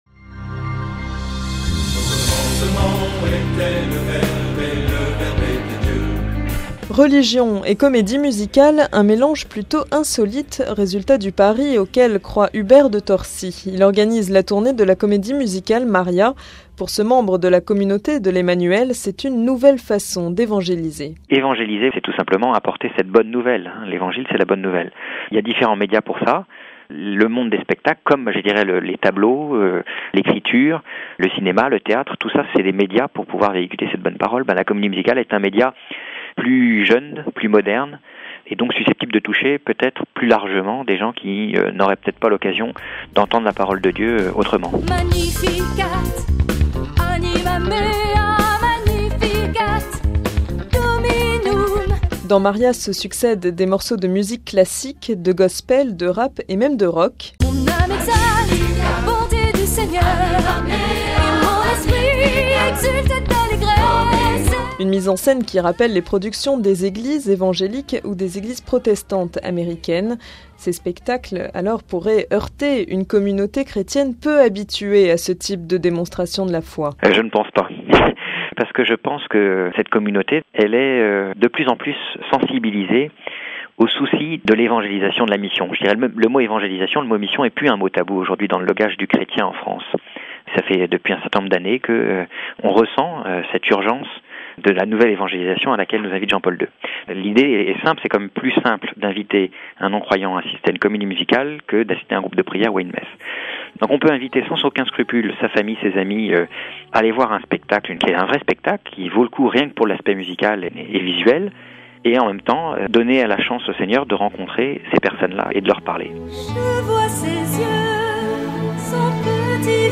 Dossier : une comédie musicale au service de l'évangélisation ?